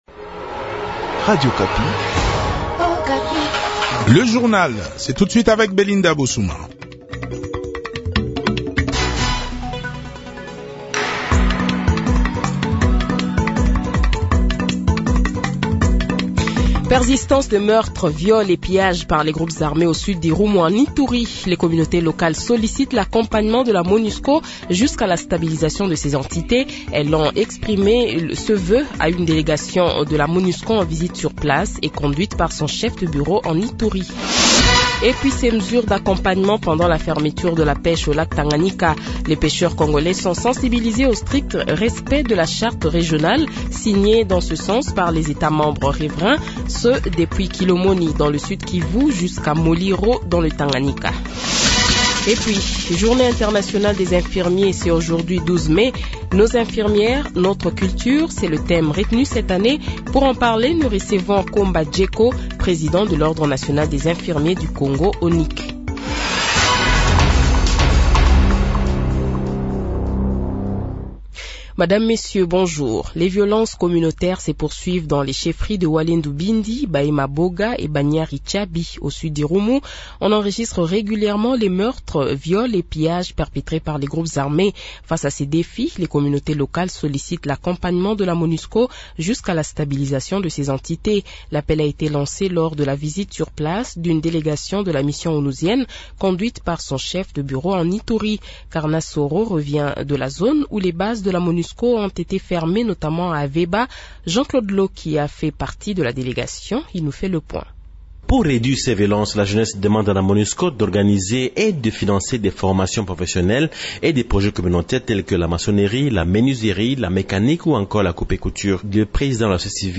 Journal Midi
Le Journal de 12h, 12 Mai 2023 :